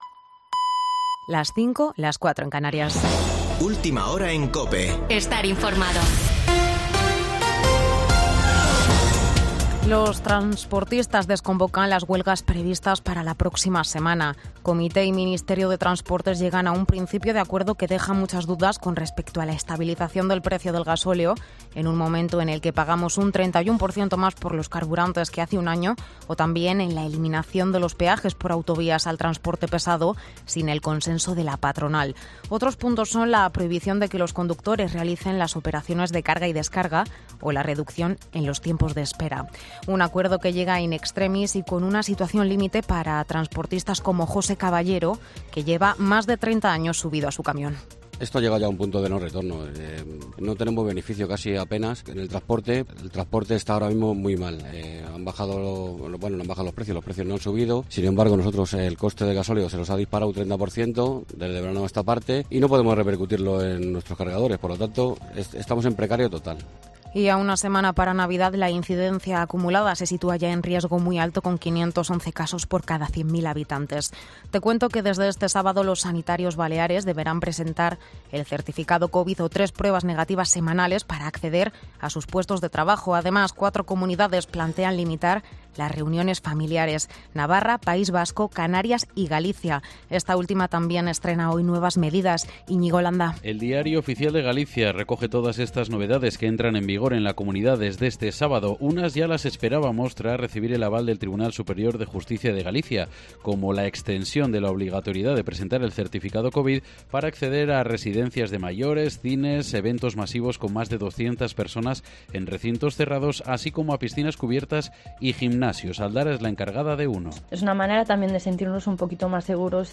Boletín de noticias COPE del 18 de diciembre de 2021 a las 05.00 horas